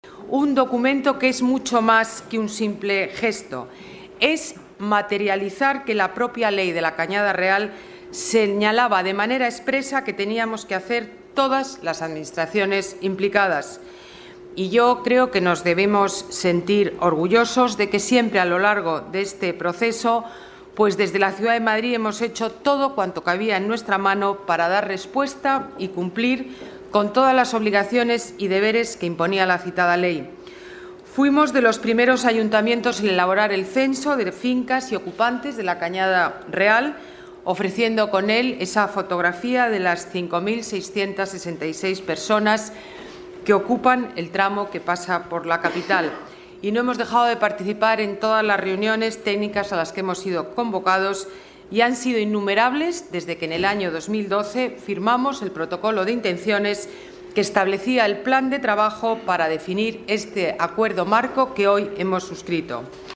Nueva ventana:Declaraciones Ana Botella: acuerdo Cañada Real